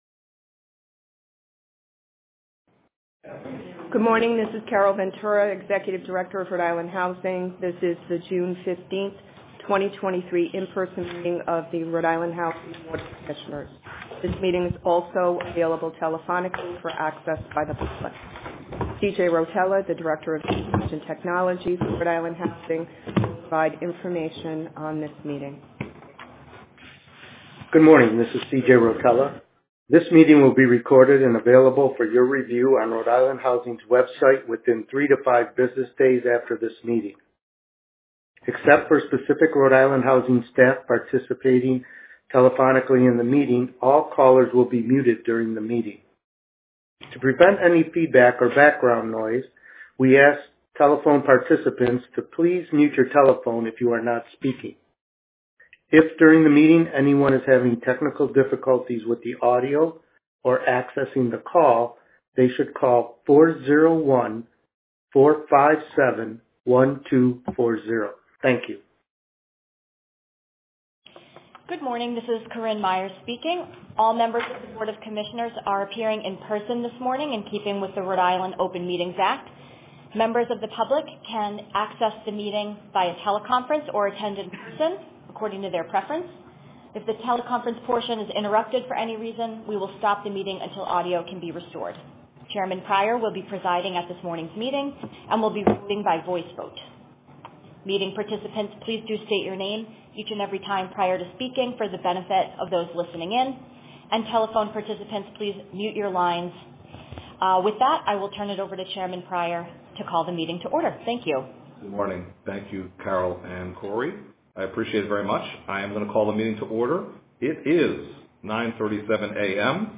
Recording of RIHousing Board of Commissioners Meeting: 06.15.2023